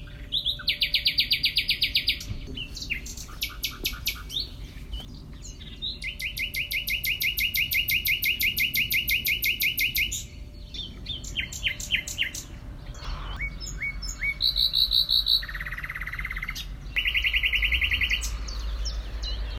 Nachtigall
Nachtigal.wav